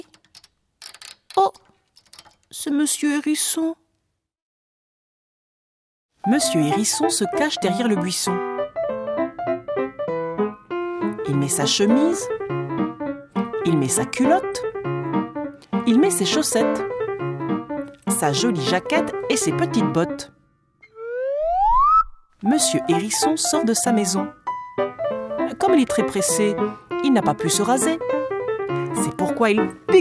Livre MP3